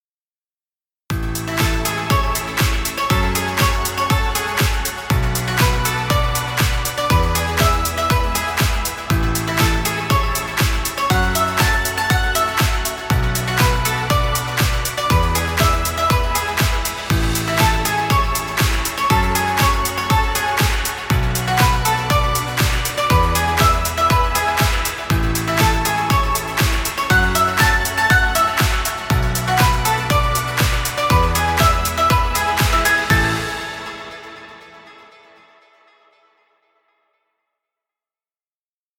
Happy corporate music.